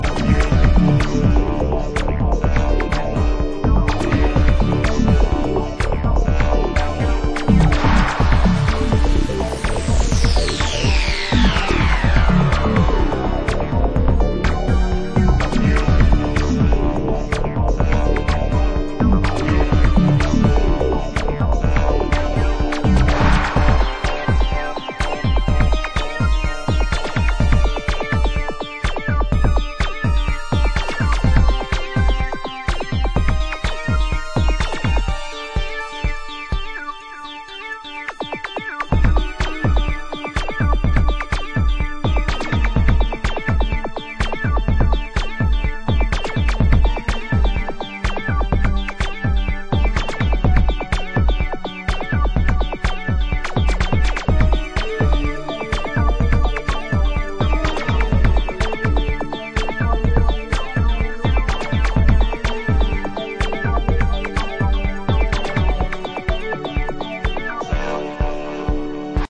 minimal dark electro tunes.. on clear vinyl…